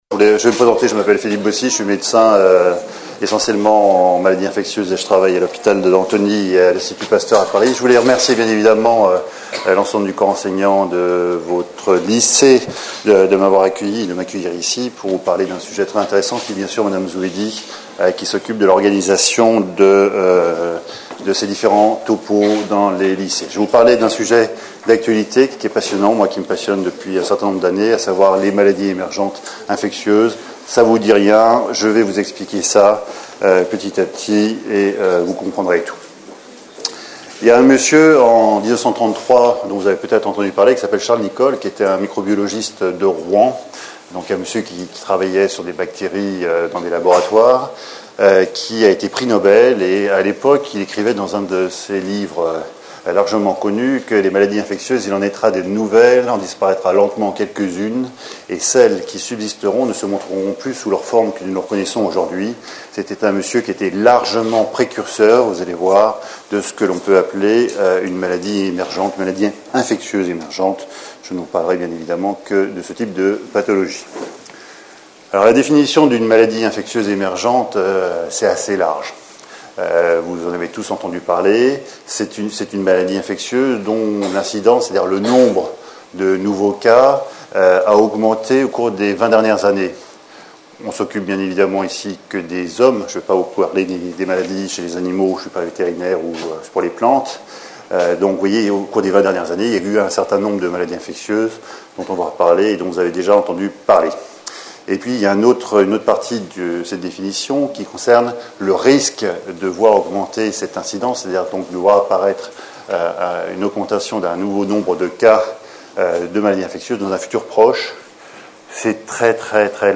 Une conférence de l'UTLS au lycée
LYCEE JEAN JAURES (93100 MONTREUIL) Partenariat Région Ile de France